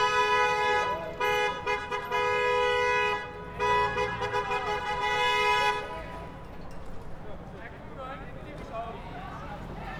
Environmental
Streetsounds
Noisepollution